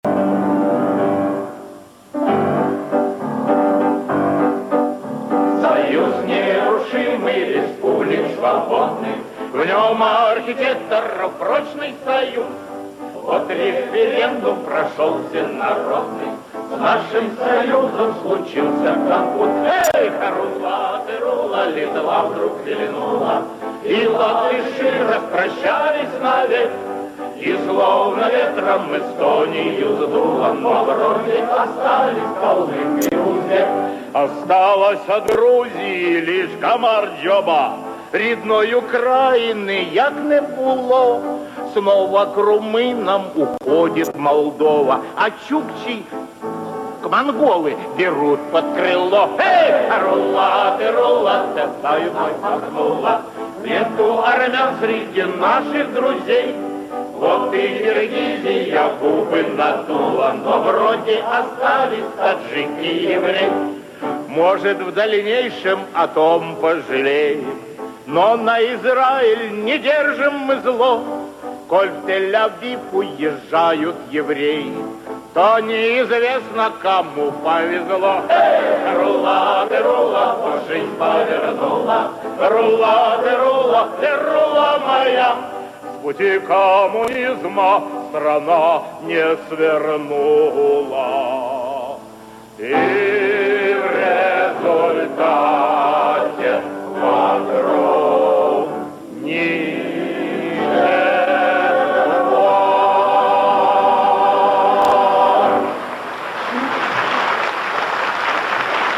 мелодия народной финской песни